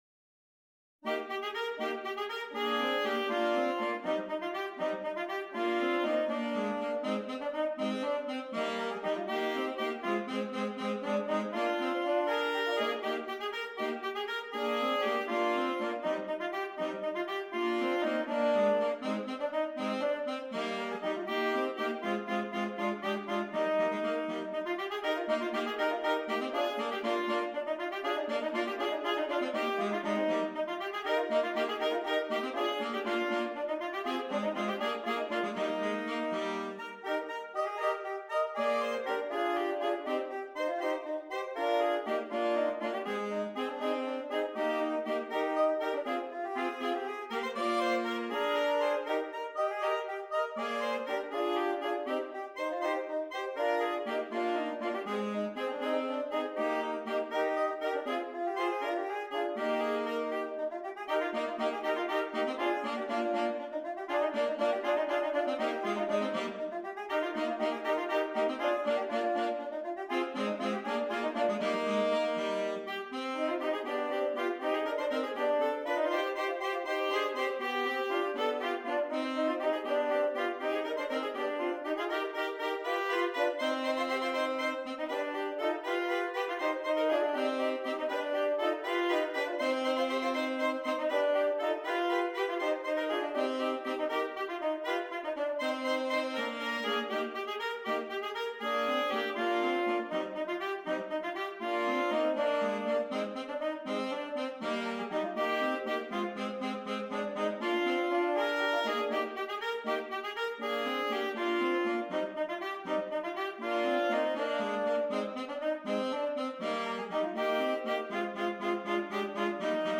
3 Alto Saxophones